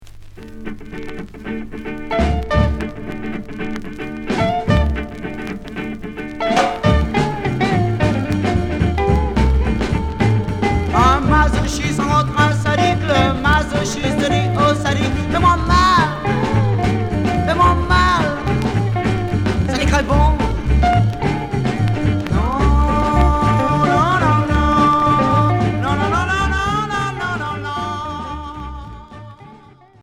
Rock garage Unique EP